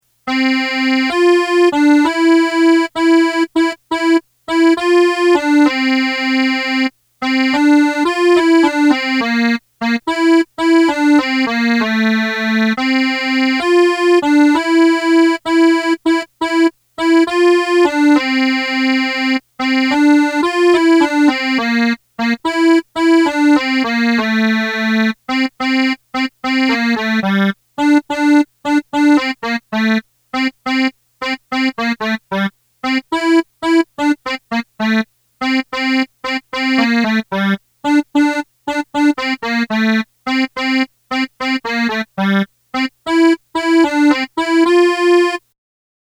sta-campagnola-melody.mp3